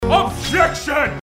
judge objection
judge-objection.mp3